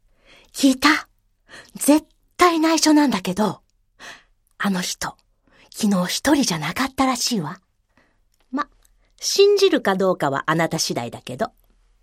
セリフ1
ボイスサンプル